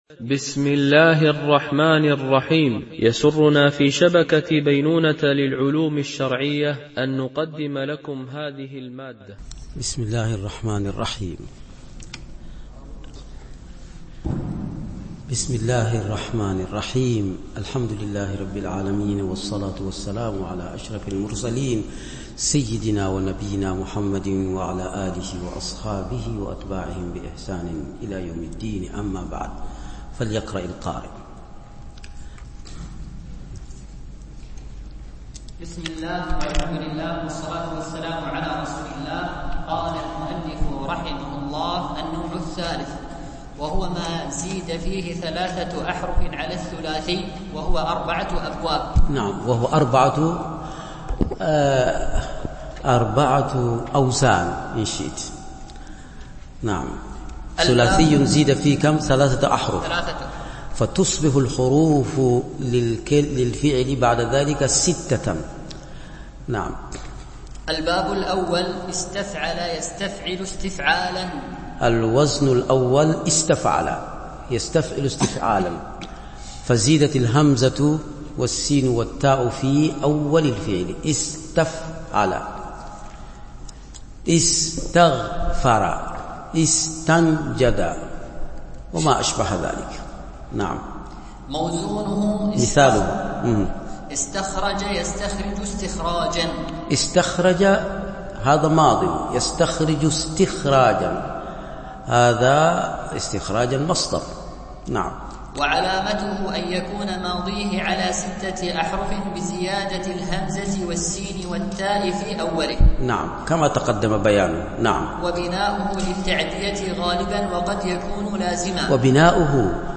دورة علمية في شرح متن البناء في الأفعال
بمسجد عائشة أم المؤمنين - دبي